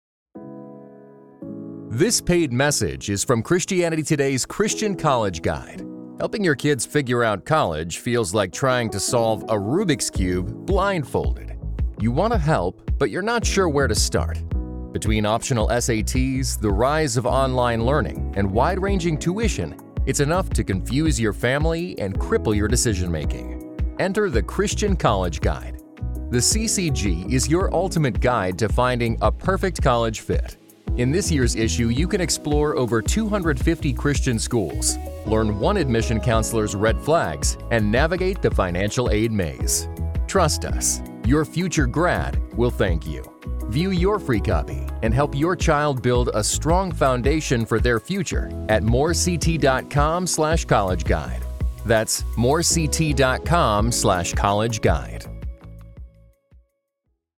Mid-roll Podcast Ad Example.mp3